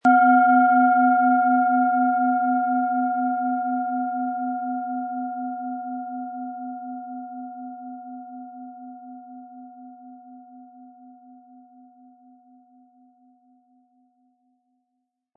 Planetenton 1
Planetenschale® Kraftvoll und Aktiv fühlen & Stärke Deine Lebenskraft mit Sonne, Ø 10,6 cm inkl. Klöppel
260-320 Gramm
In der Lieferung enthalten ist kostenfrei ein passender Klöppel speziell für diese Schale, damit die Töne wohlklingend zur Geltung kommen.